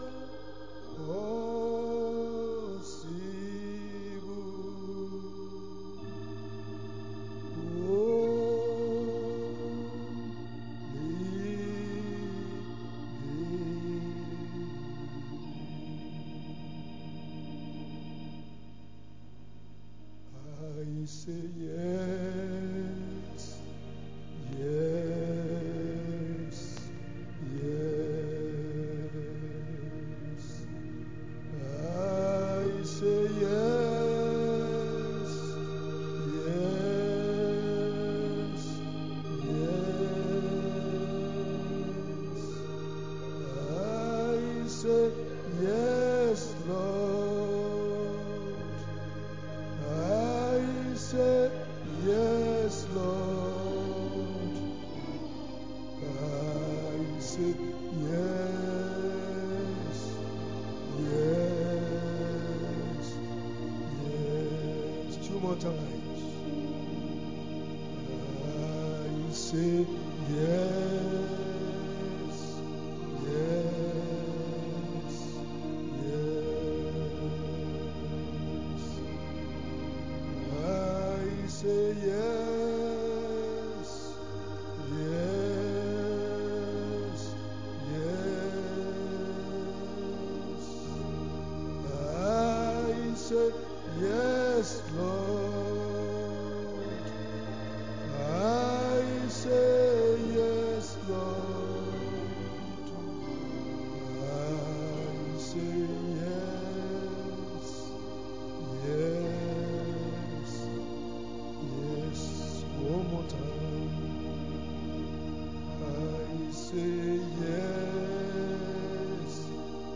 Wed. Prayer Meeting 11-02-26_Evang.